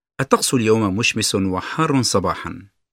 [ат̣т̣ак̣суль йоум му́шмисун уа х̣а̄ррун с̣аба̄х̣ан]